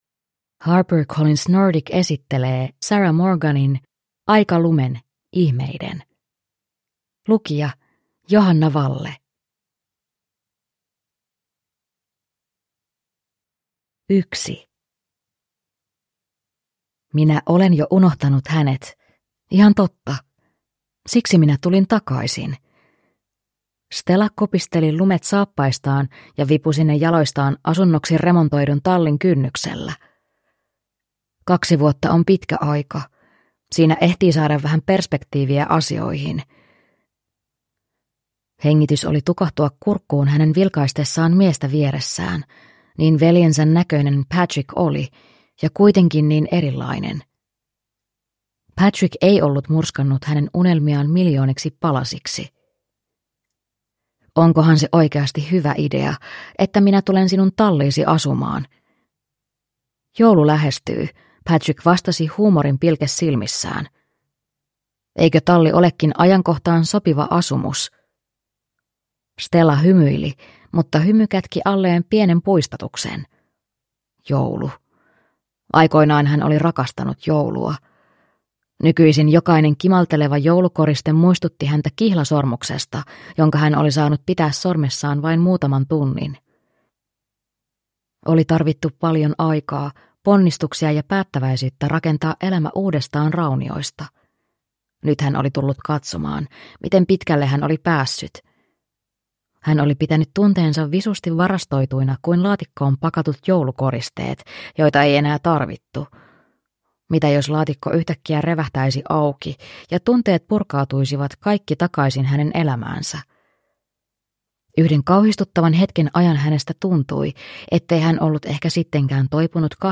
Aika lumen, ihmeiden – Ljudbok